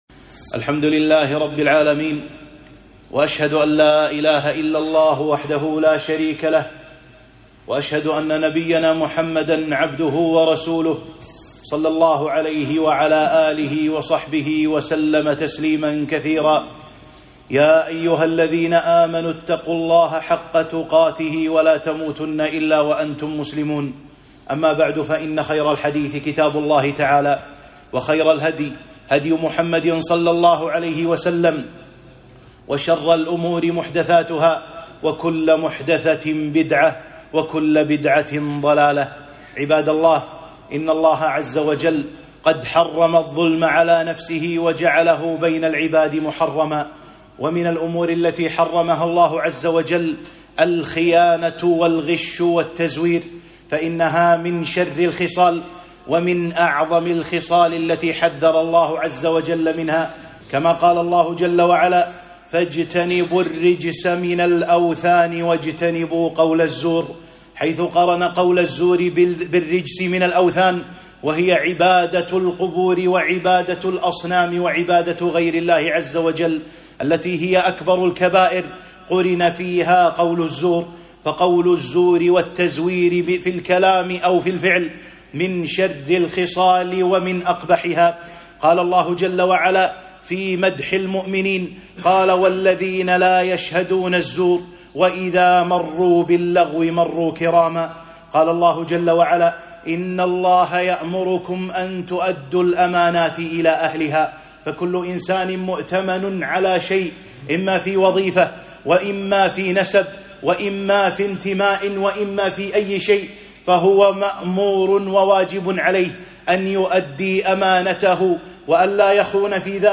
خطبة - الحذر من الغش والتزوير